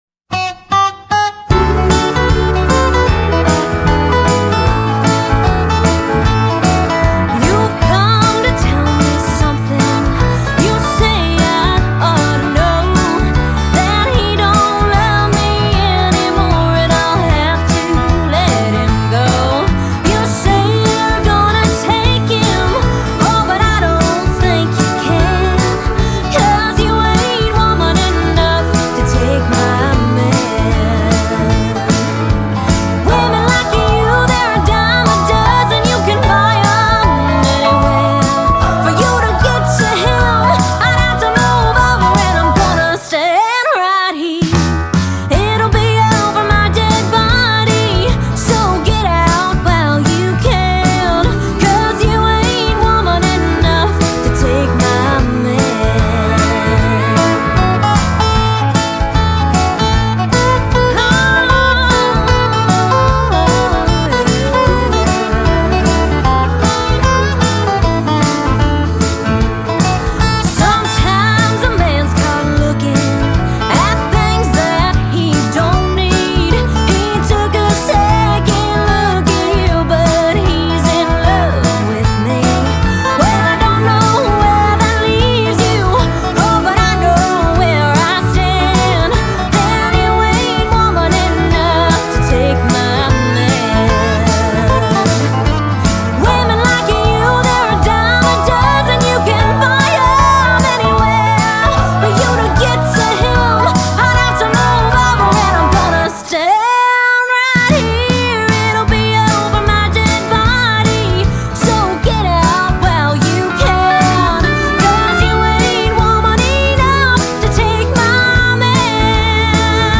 Genre: Country.